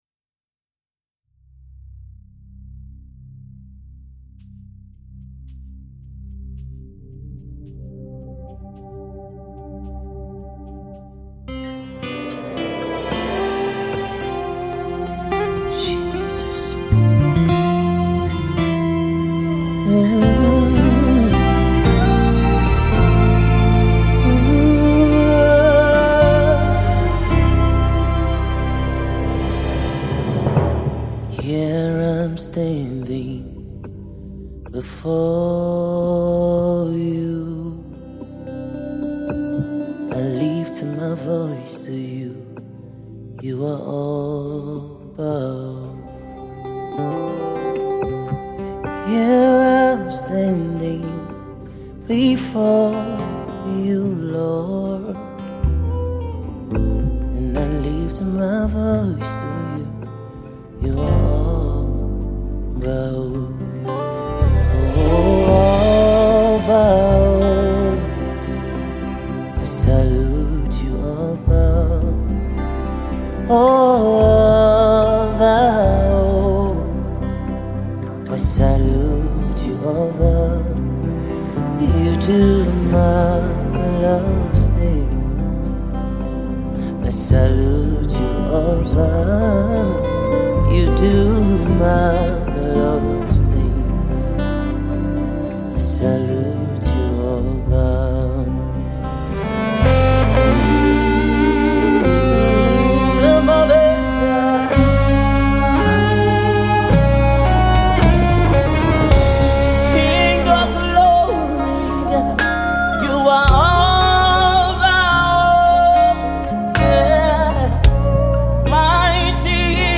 the Gospel music minister
worship song